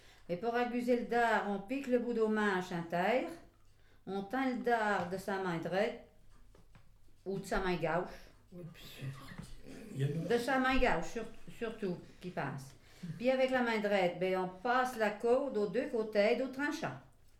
Maraîchin
Collecte de locutions vernaculaires